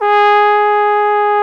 Index of /90_sSampleCDs/Roland LCDP12 Solo Brass/BRS_Trombone/BRS_Tenor Bone 1